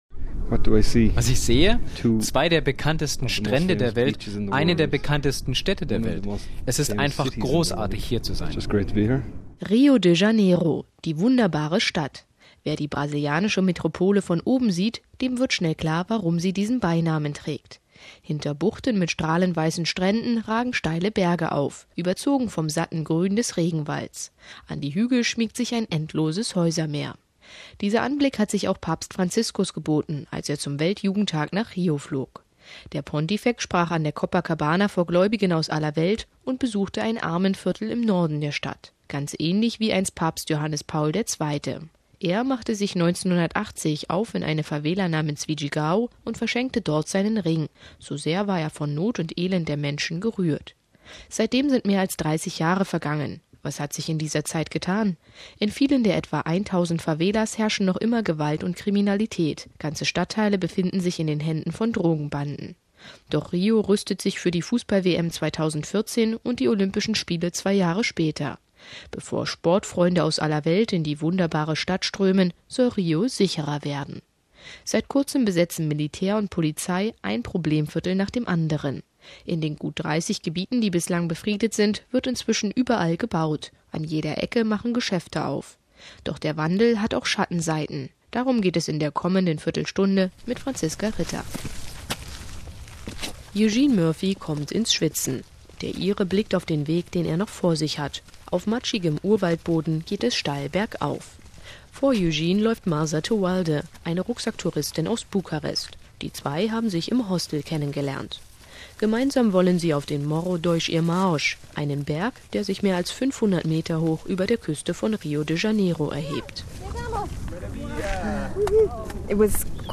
Bevor Sportfreunde aus aller Welt in die Wunderbare Stadt strömen, soll Rio sicherer werden. Eine Reportage